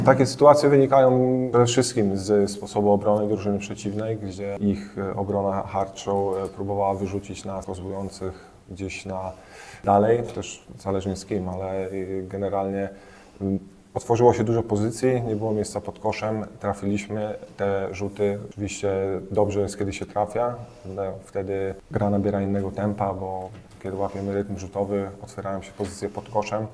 zaznaczał koszykarz niebiesko-biało-zielonych